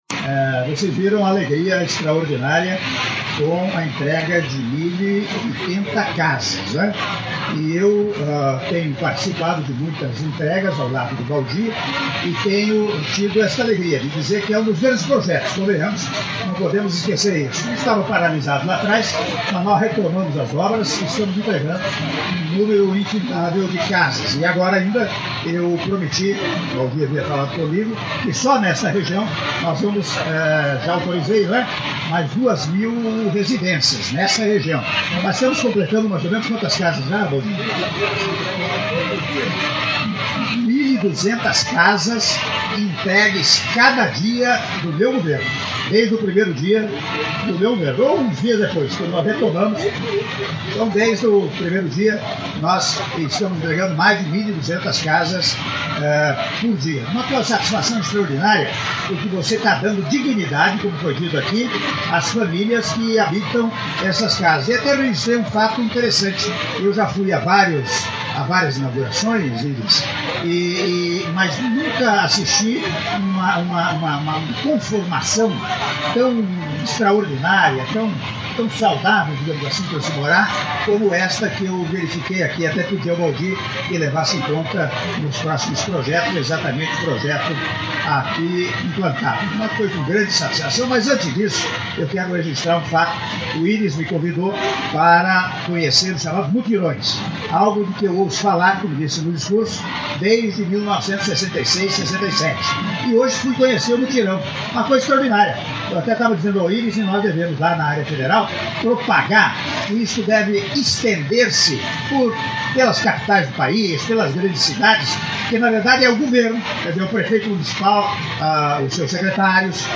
Áudio da entrevista coletiva concedida pelo Presidente da República, Michel Temer, após entrega de 1.080 unidades habitacionais do Residencial Jardim do Cerrado VI - Goiânia/GO - (02min41s)